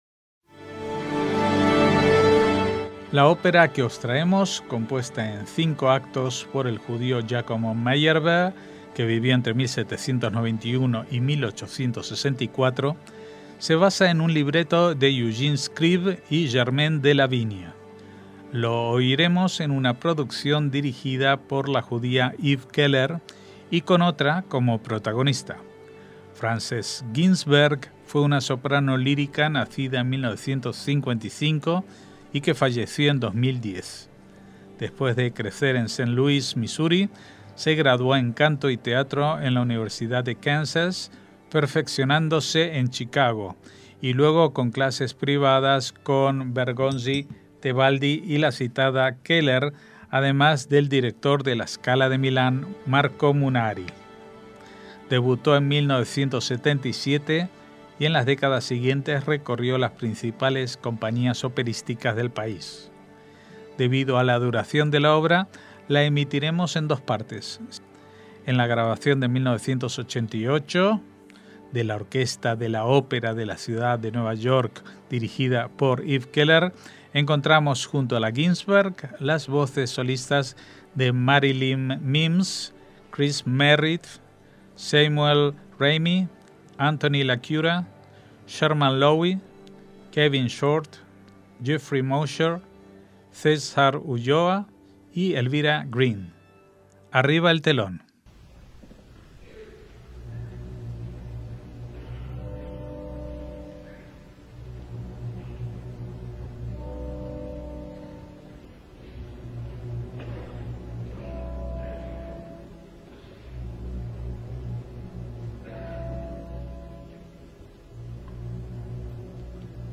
ÓPERA JUDAICA – La ópera que os traemos, compuesta en cinco actos por el judío Giacomo Meyerbeer, que vivió entre 1791 y 1864, se basa en un libreto de Eugene Scribe y Germain Delavigne.